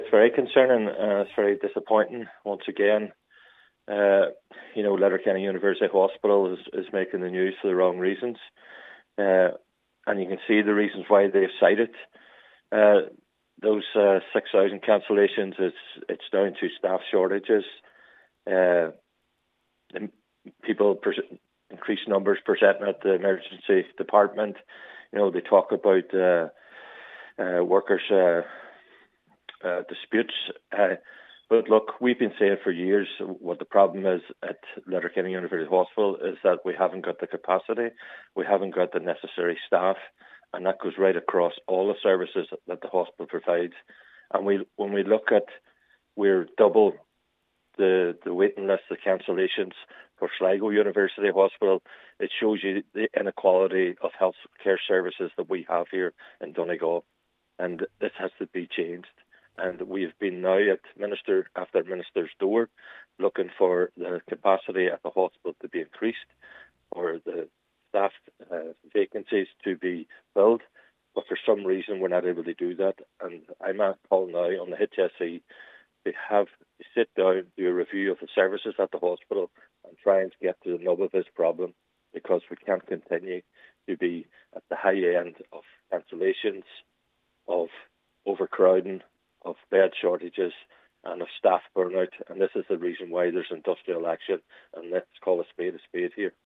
Councillor Gerry McMonagle, a member of the Regional Health Forum West say the figures are very concerning.